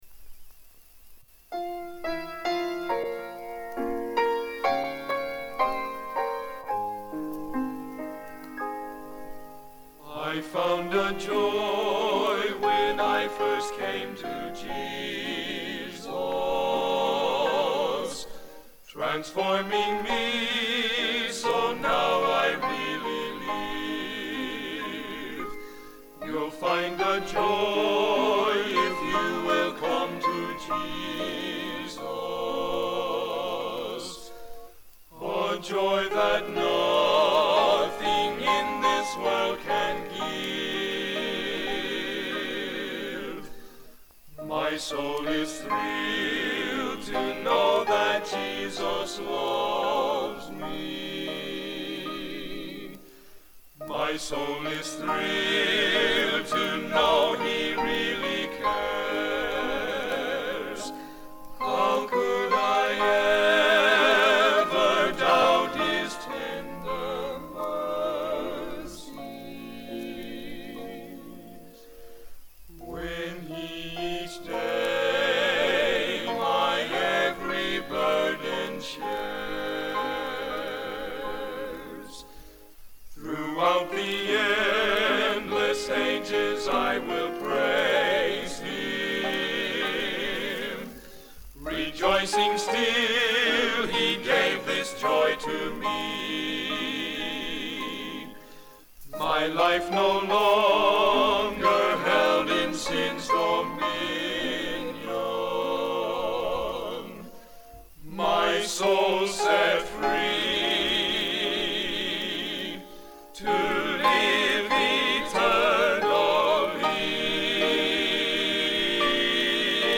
To God Be The Glory - BNC Collegiate Quartet